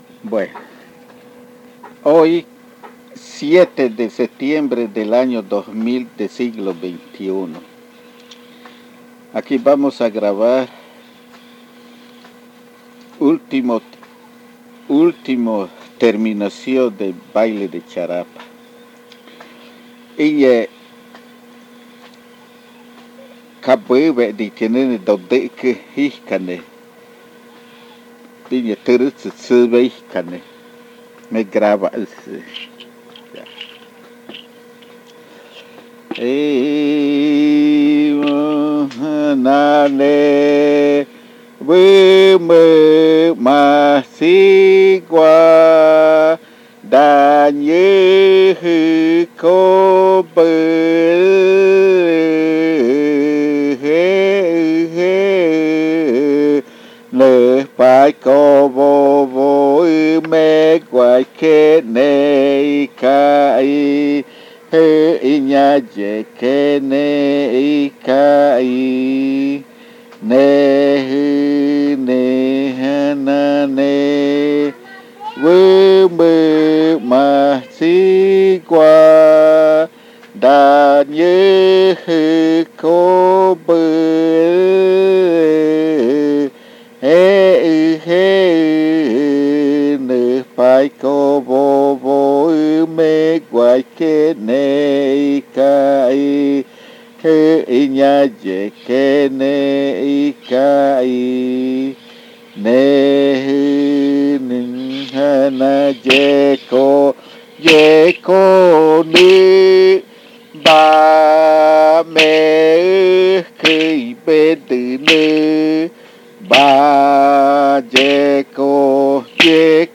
Baile Tʉrɨ majtsi (baile de charapa) del pueblo miraña.
El audio contiene los lados A y B del casete #6.